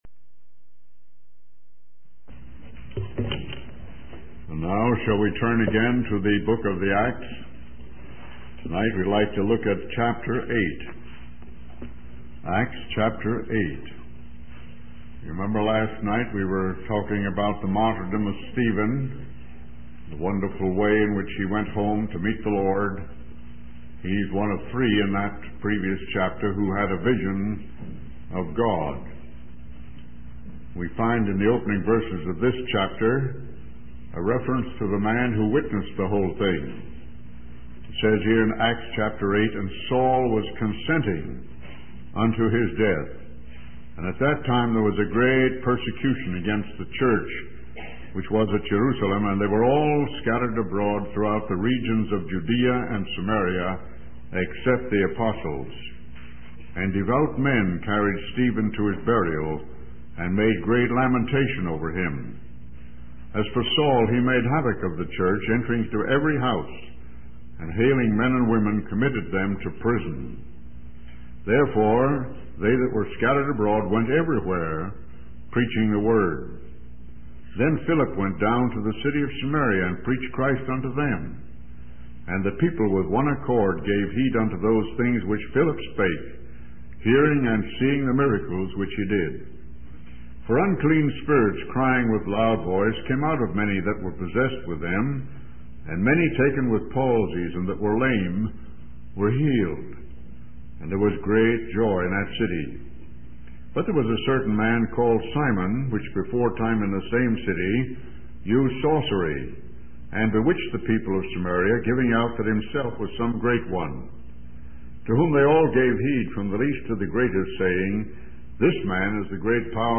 In this sermon, the speaker discusses the theme of being a prepared soul and instrument for God's message. He emphasizes the importance of reading and understanding the Word of God.